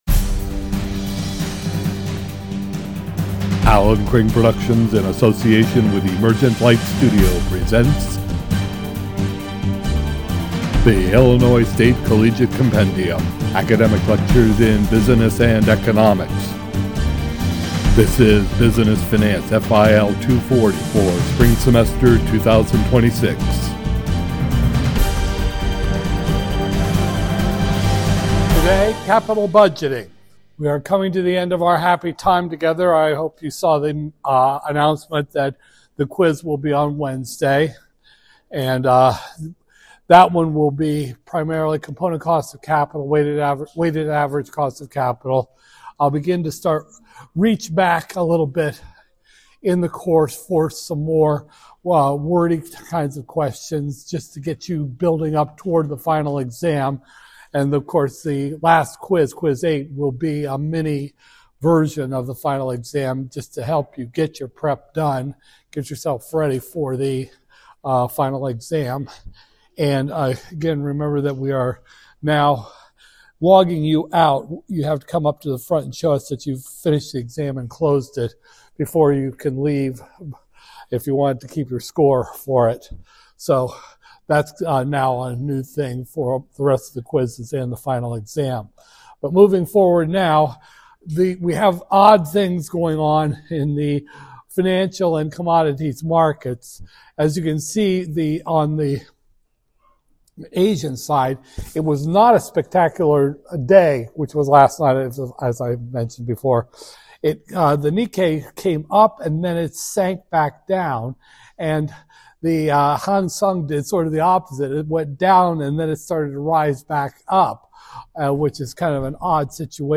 Business Finance, FIL 240-001, Spring 2026, Lecture 21